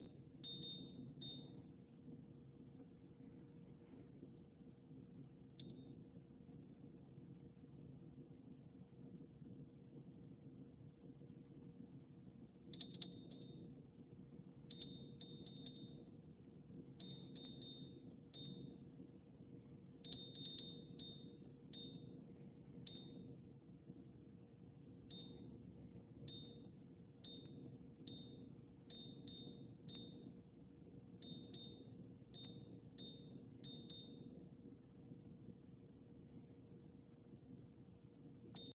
LG LRSC21951ST Refrigerator Dispenser Display Random Beep and Button Presses
Any thoughts or opinions on what is going on here otherwise? trying to attach a video showing the dispenser display beeping and acting like buttons for temp and lock are being pressed but it’s not.being touched.